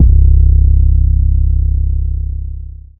DEEDOTWILL 808 42.wav